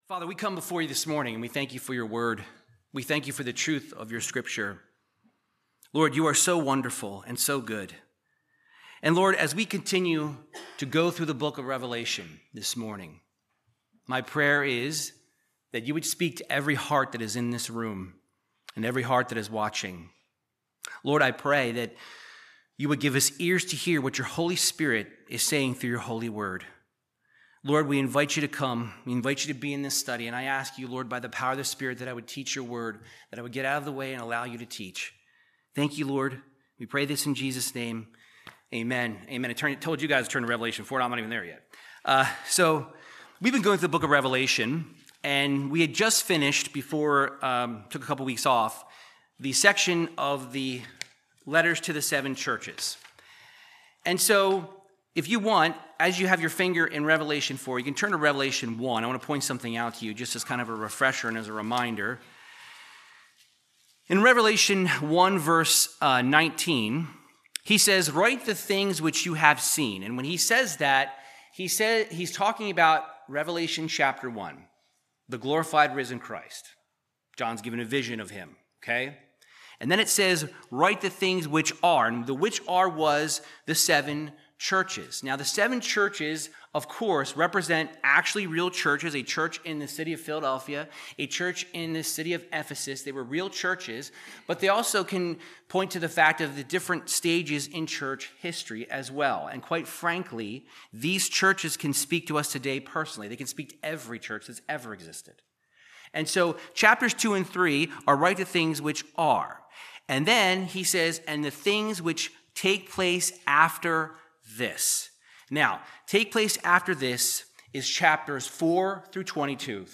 Verse by verse Bible teaching through Revelation chapter 4